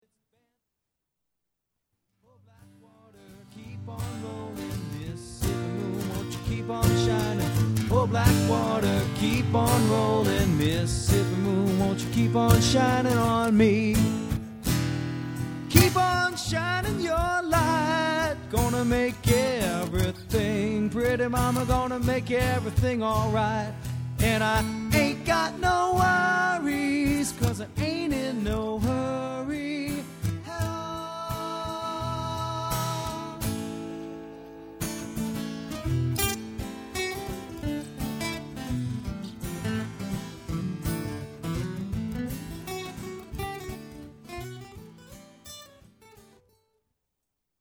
Live Song Samples
Live Demos